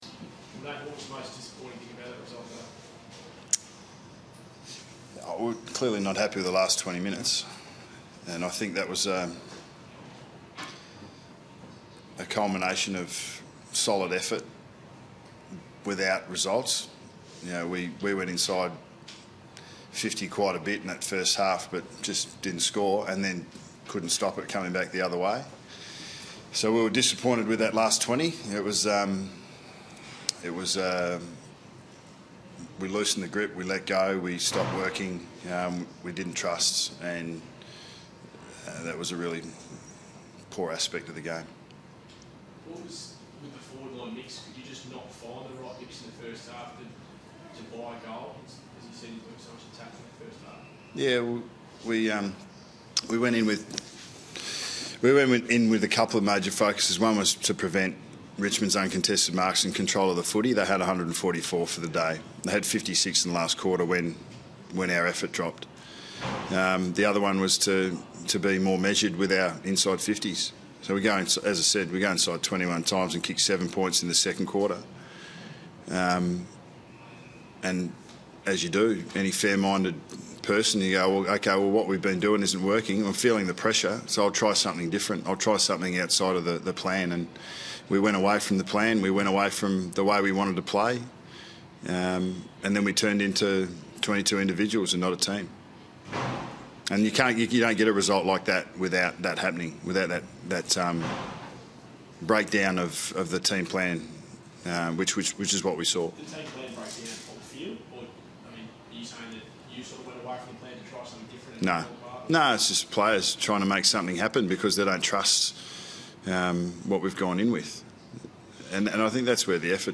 Watch coach Nathan Buckley take questions from the media following Collingwood's loss to Richmond in round 21.